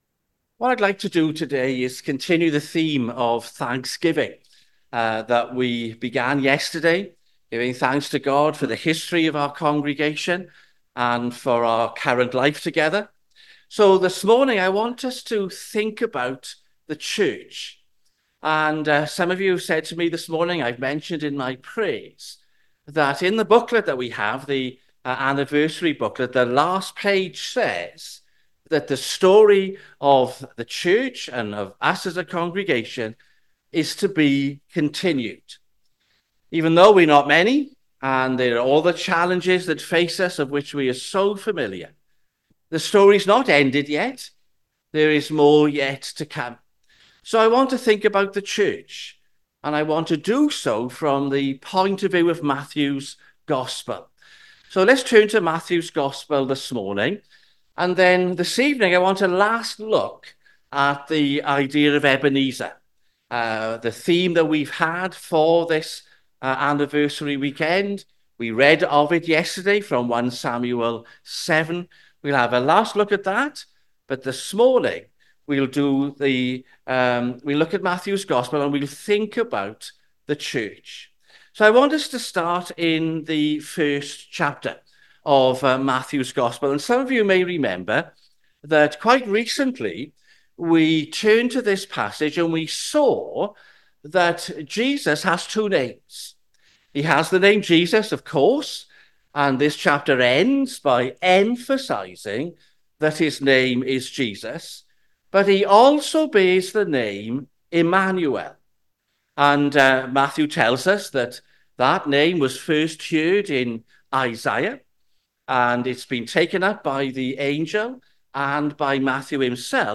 Sermons Tabernacle Church - 20th October 2024 - The Church Play Episode Pause Episode Mute/Unmute Episode Rewind 10 Seconds 1x Fast Forward 30 seconds 00:00 / 33:22 Subscribe Share RSS Feed Share Link Embed